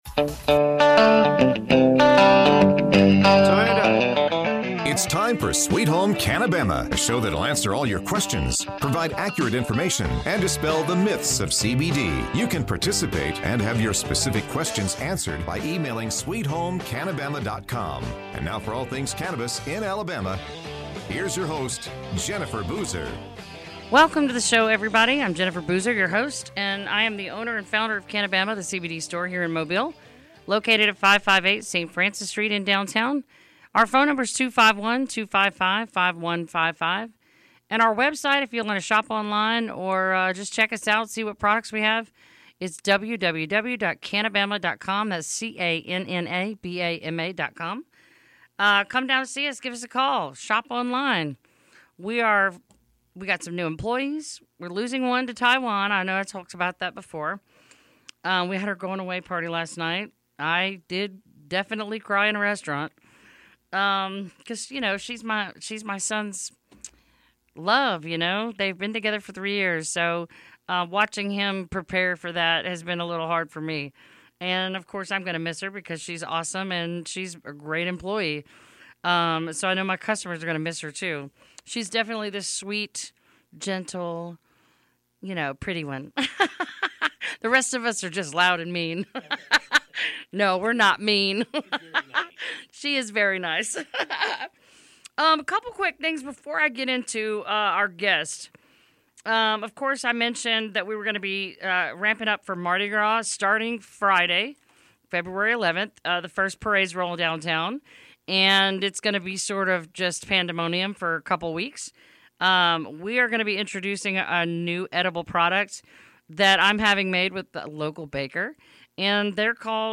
Alabama hosts this weekly show about the CBD industry, interviews